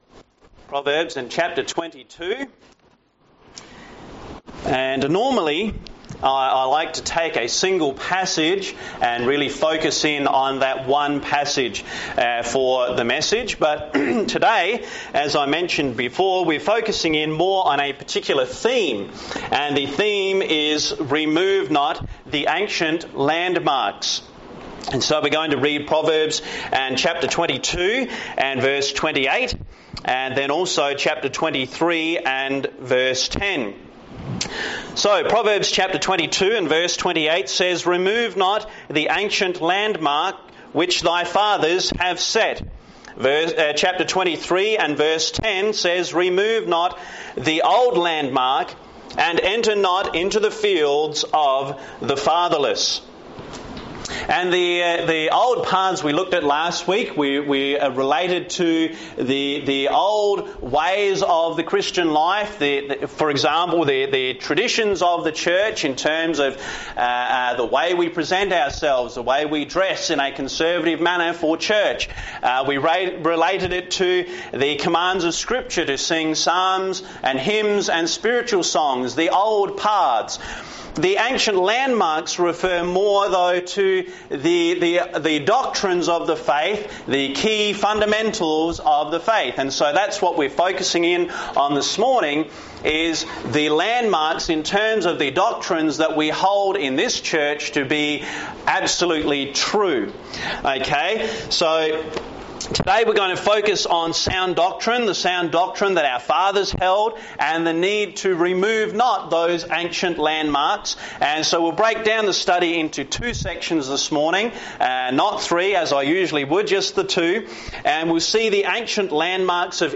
Sermon Outline The Ancient Landmarks of Israel The Ancient Landmarks of Today The Setting of the Landmarks The LORD God told Moses to instruct Israel regarding the division of the Promised Land between the tribes.